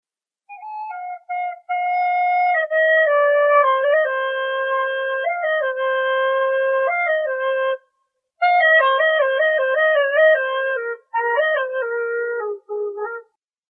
Tag: 120 bpm Jazz Loops Flute Loops 2.30 MB wav Key : Unknown